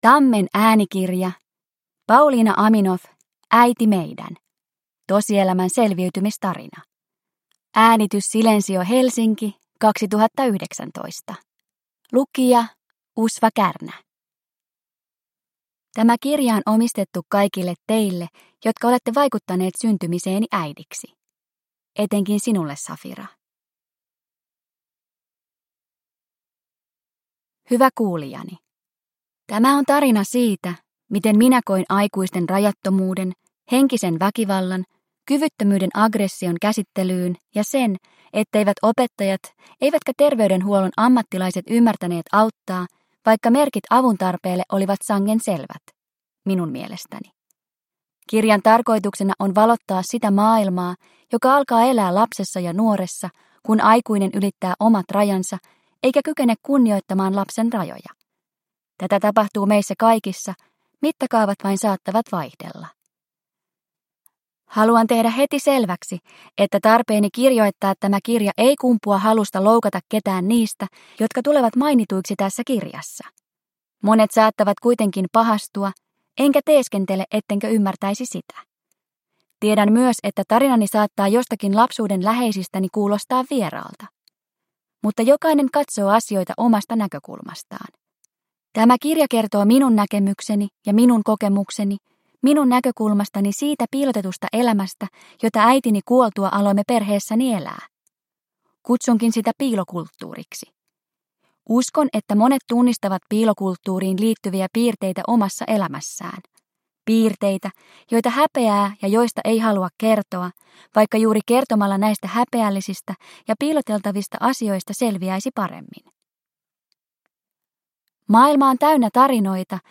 Äiti meidän – Ljudbok – Laddas ner